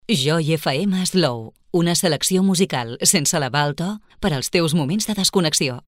Indicatiu Costa Brava - Girona.
Indicatiu amb la freqüència d'FM.